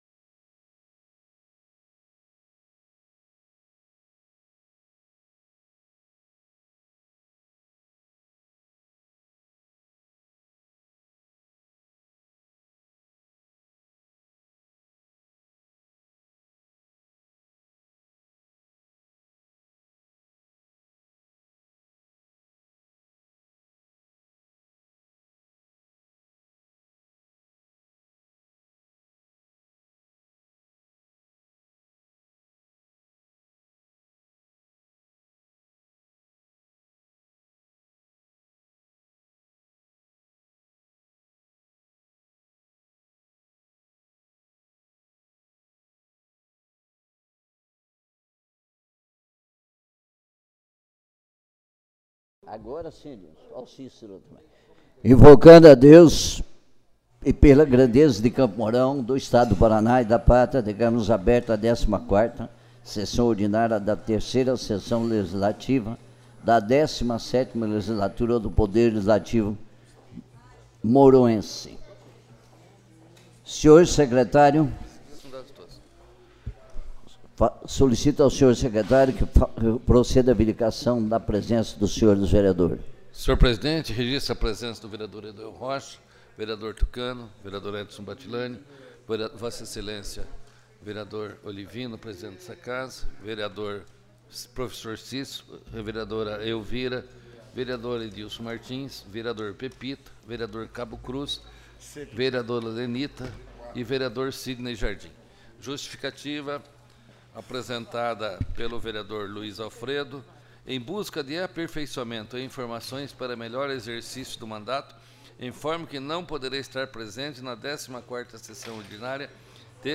14ª Sessão Ordinária